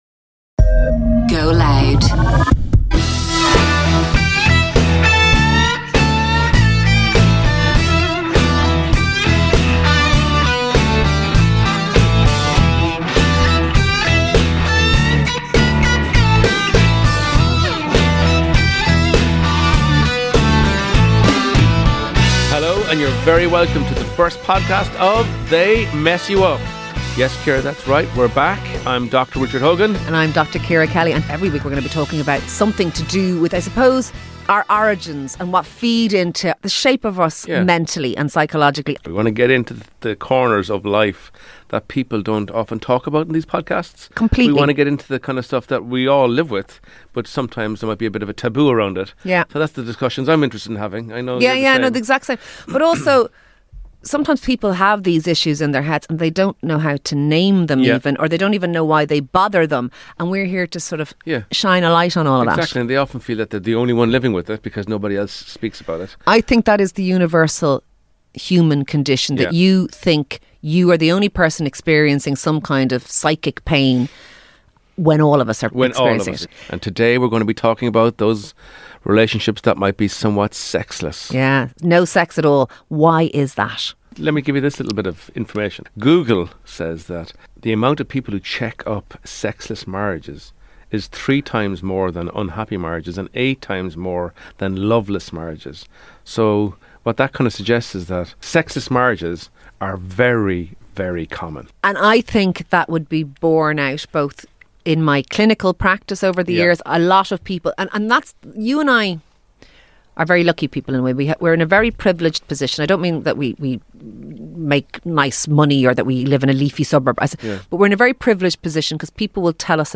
They Mess You Up is a straight-talking show that unpacks messy, everyday relationship and mental-health issues with a mix of clinical perspective, personal anecdotes and wry, no-nonsense banter.